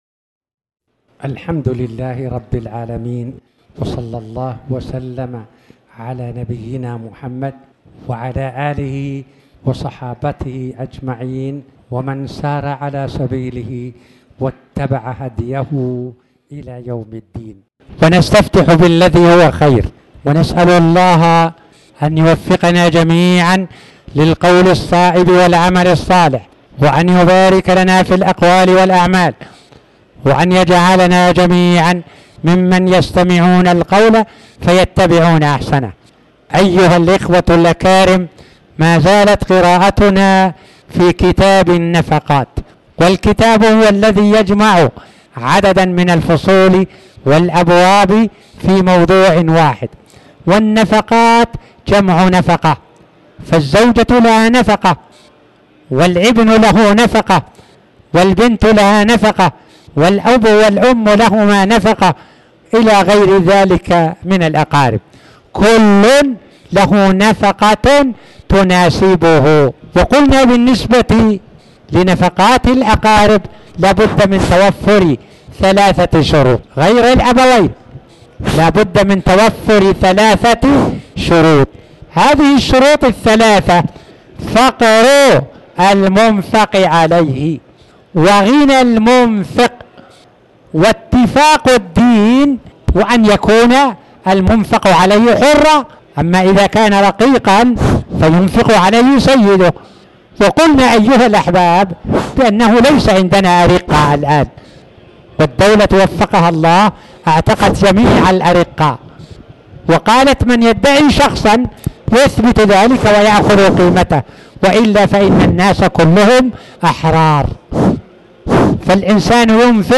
تاريخ النشر ١٨ جمادى الآخرة ١٤٣٩ هـ المكان: المسجد الحرام الشيخ